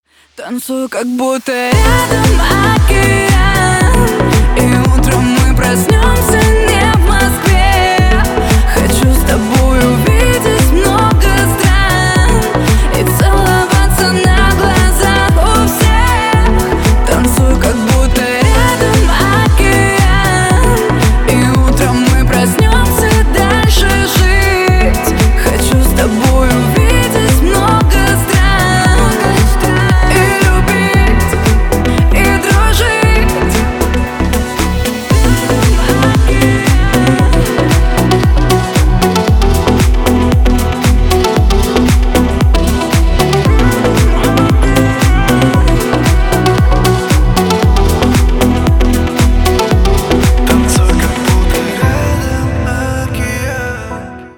• Песня: Рингтон, нарезка